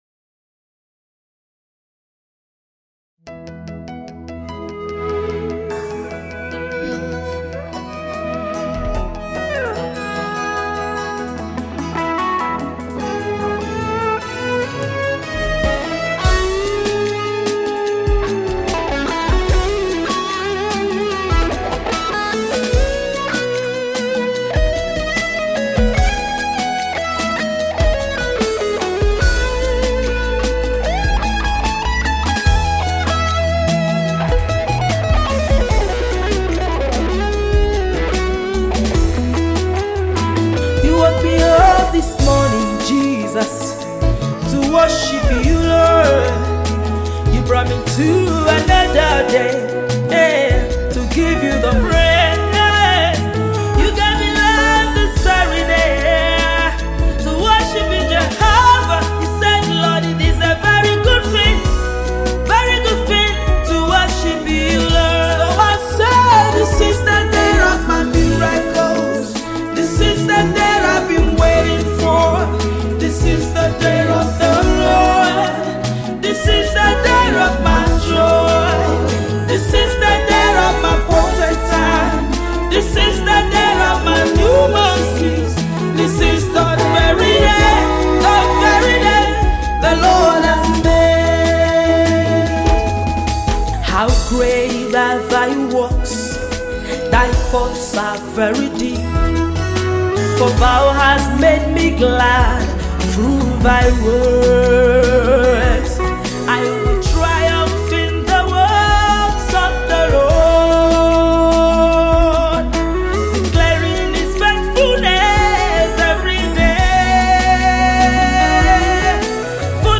Versatile gospel music minister